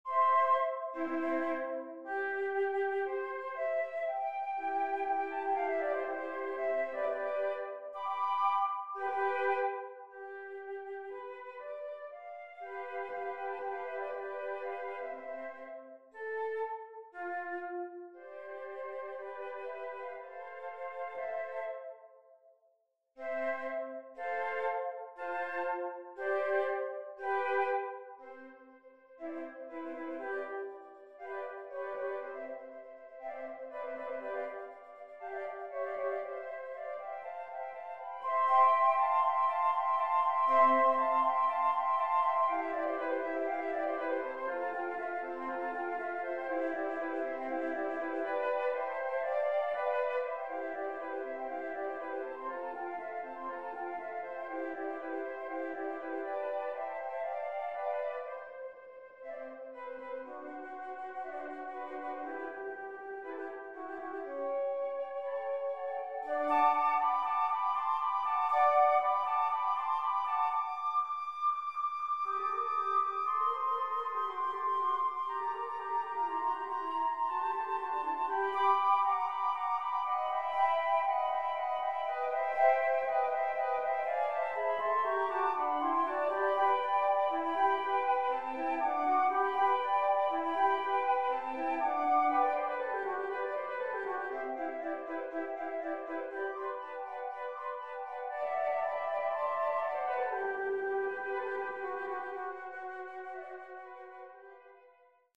für 3 Flöten